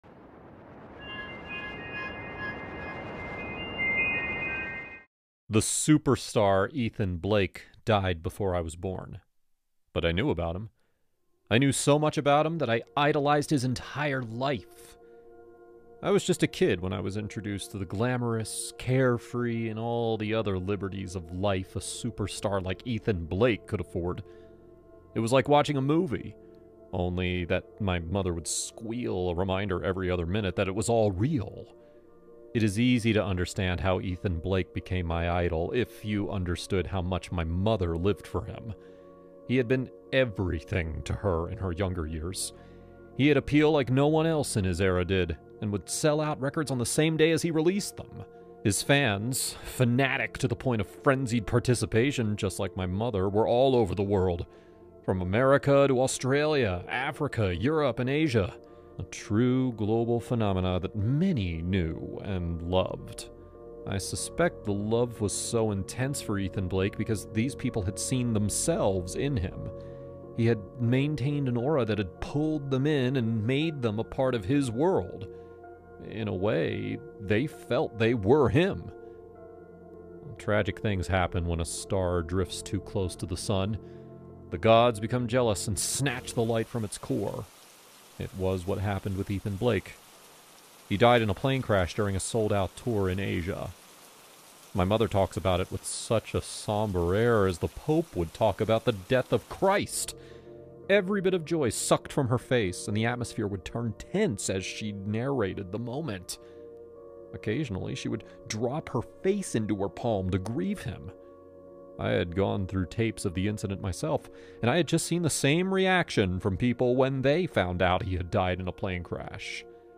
This episode contains brief promotional messages at the beginning.Inside Haunted House, every shadow breathes, every whisper trembles, and every story drags you deeper into a darkness you thought you could control.